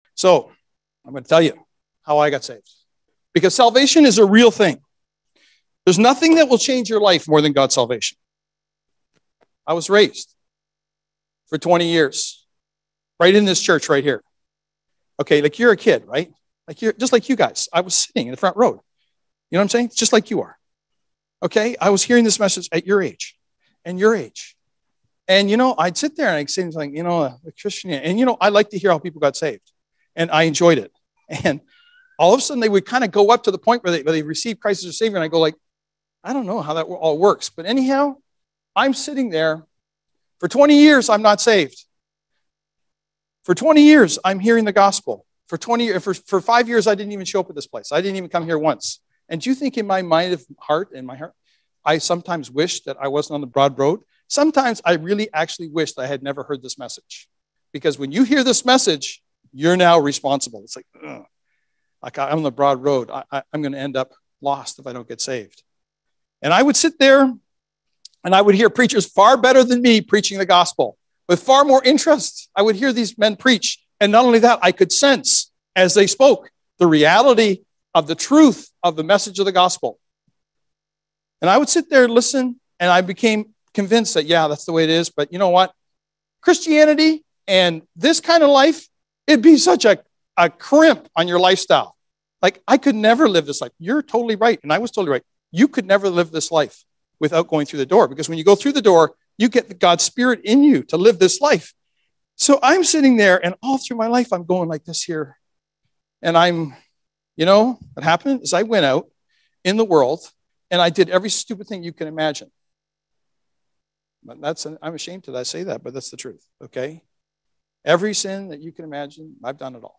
(Recorded in Sarnia Gospel Hall, ON, Canada, 15th Mar 2025)